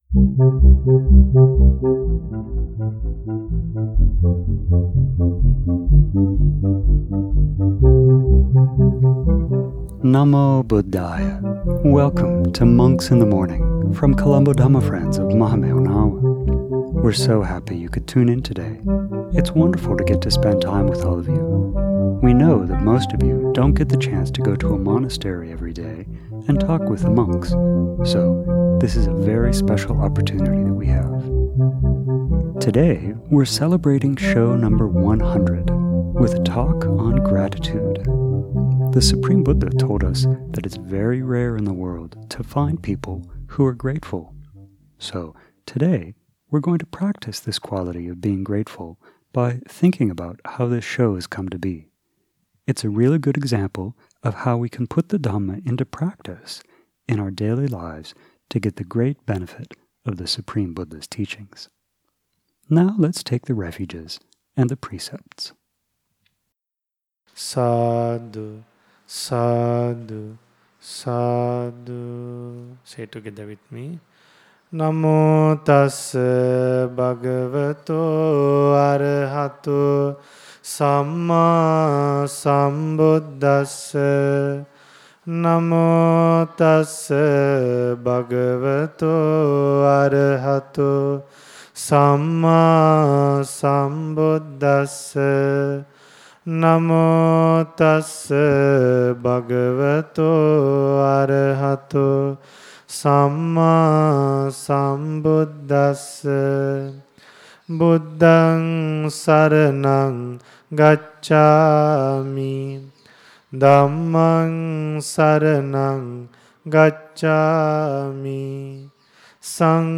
Today we are celebrating show number 100 with a talk on gratitude. The supreme Buddha told us that it is very rare in the world to find people who are ... Read more